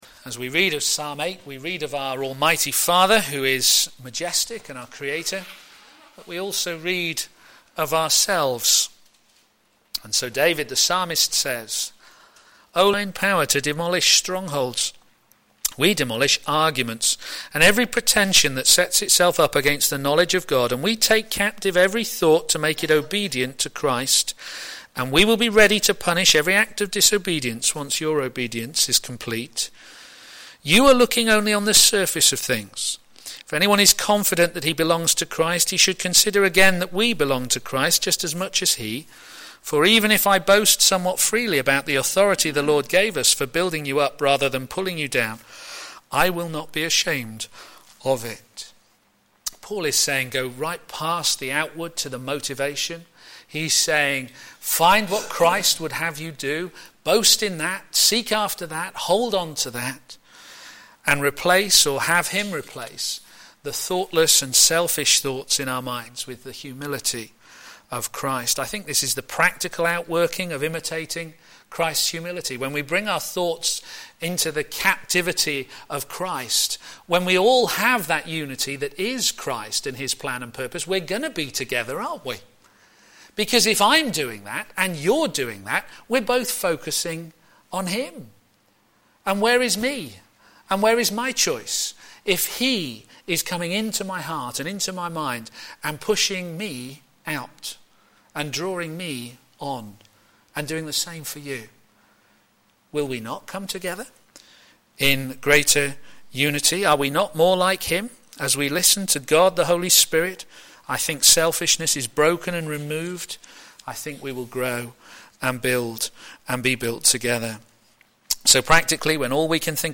Media Library Media for a.m. Service on Sun 15th Jun 2014 10:30 Speaker
Theme: What the Bible says about humanity Sermon In the search box below, you can search for recordings of past sermons.